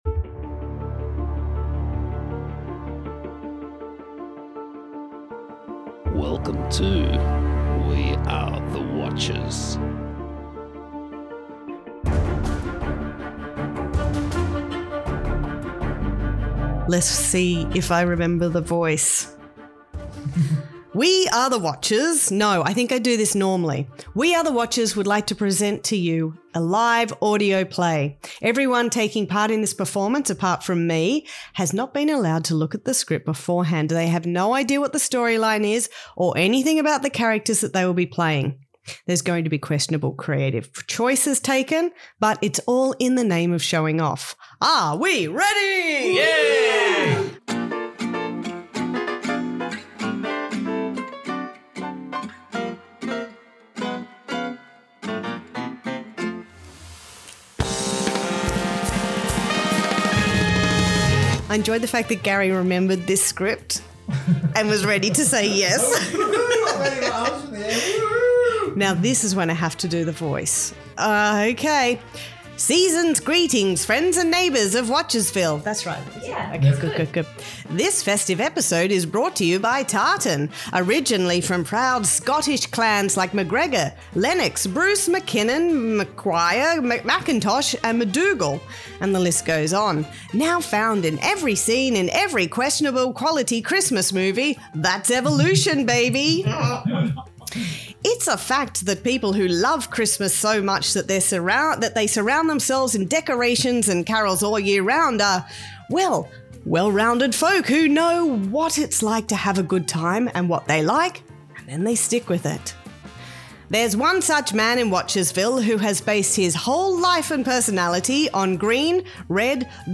Everyone taking part in this performance has not been allowed to look at the script beforehand. They have no idea what the storyline is or anything about the characters they will be playing.